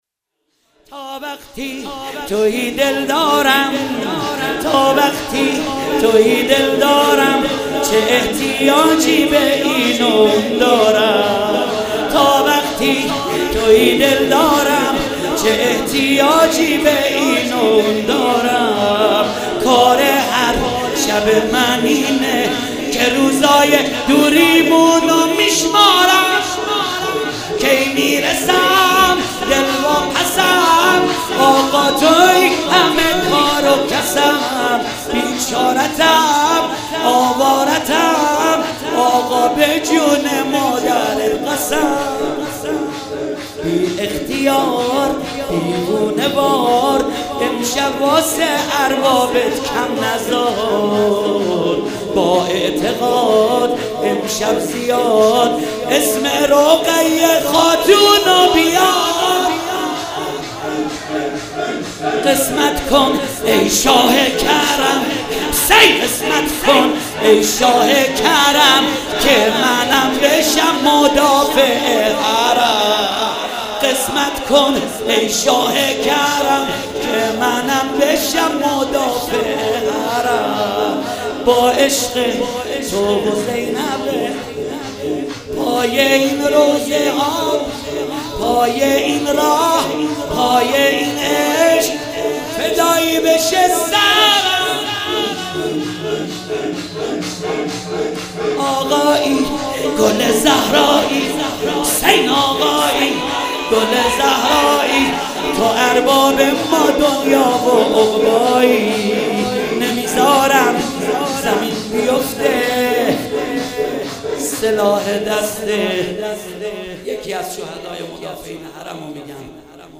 مناسبت : شب سیزدهم رمضان
قالب : شور